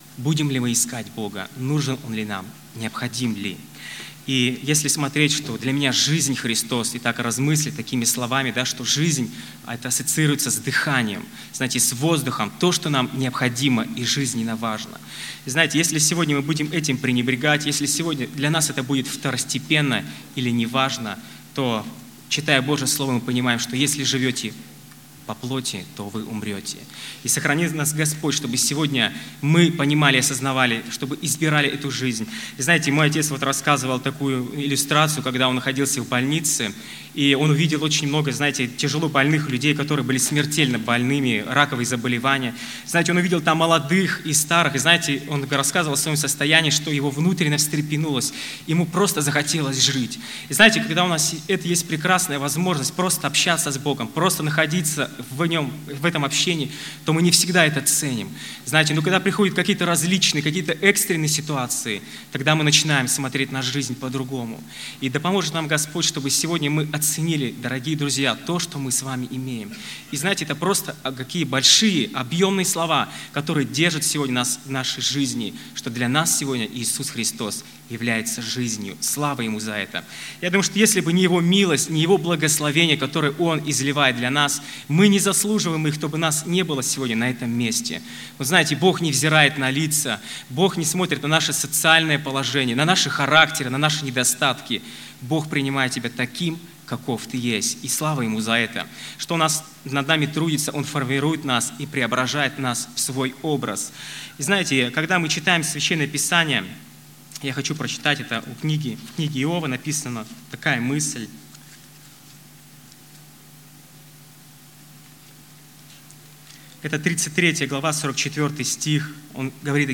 Конференция молодежи ОЦХВЕ Сибири 2019
Проповедь